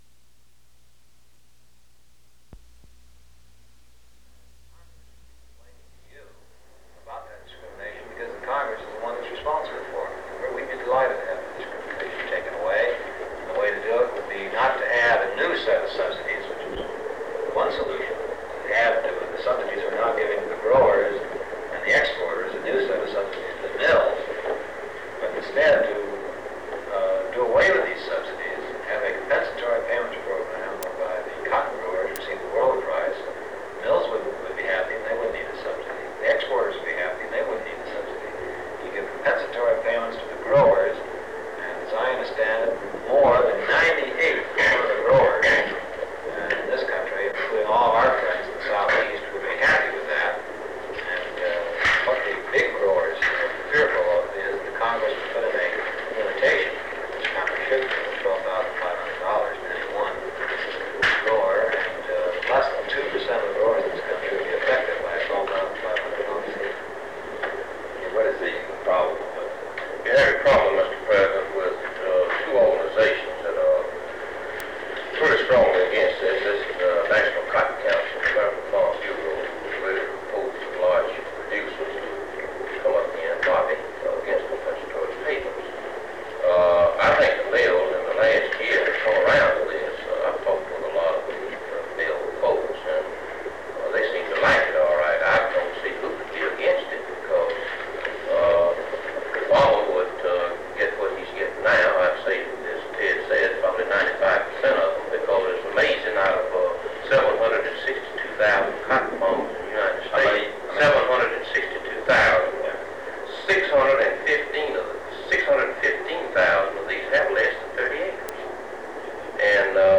Secret White House Tapes | John F. Kennedy Presidency Meeting on Trade and Textile Policy Rewind 10 seconds Play/Pause Fast-forward 10 seconds 0:00 Download audio Previous Meetings: Tape 121/A57.